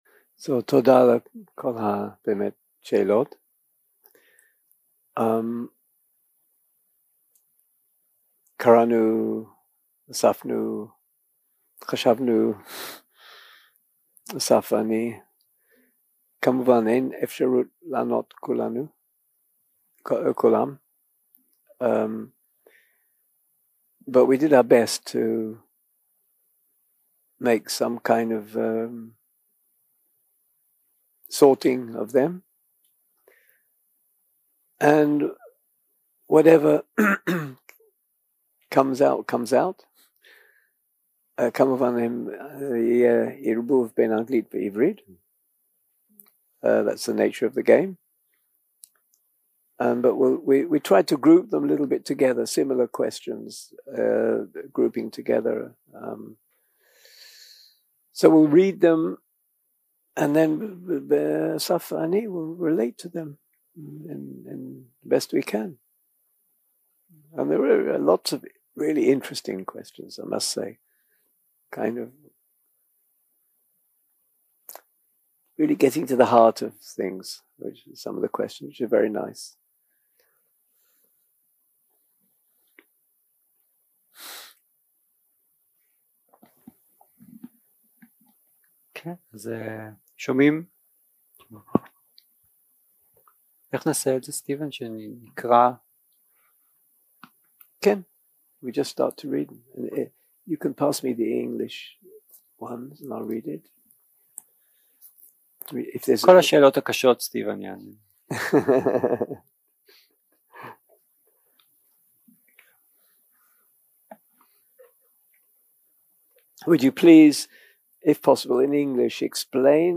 Dharma type: Questions and Answers שפת ההקלטה